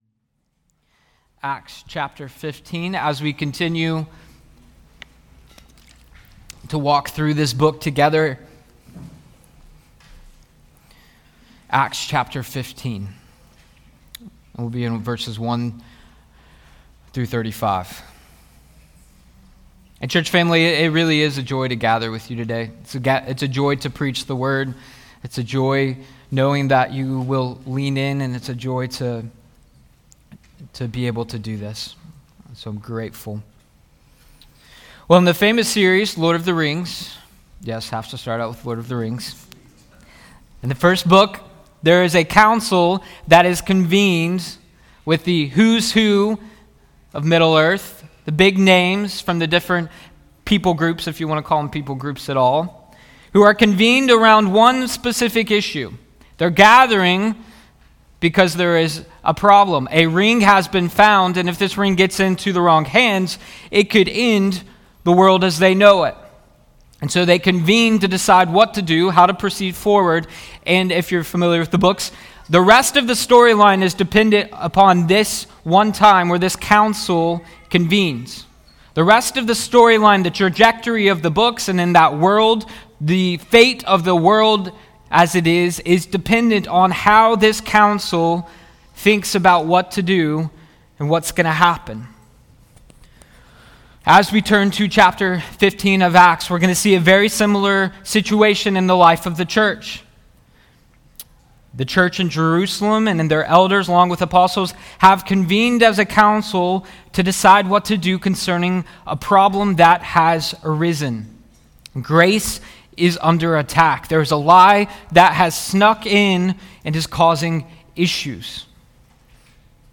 Sermons | Risen Hope Church